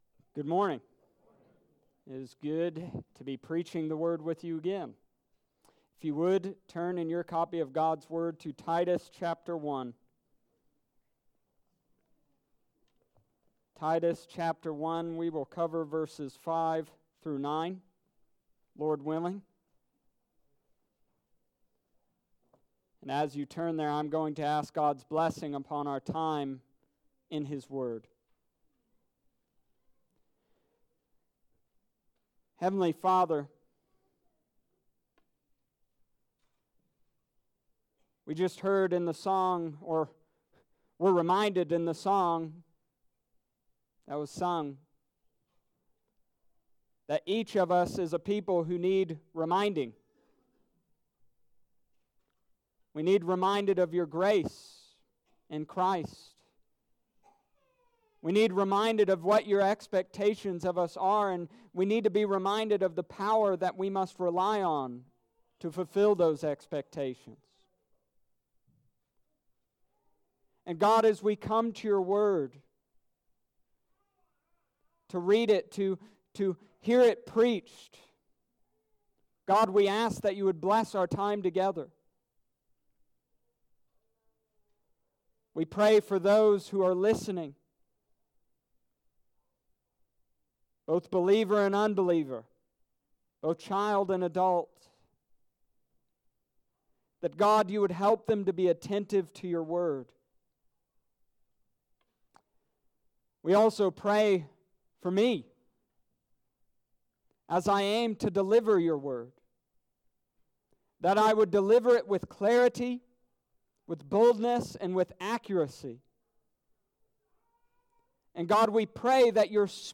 Summary of Sermon: This week, we continued the book of Titus.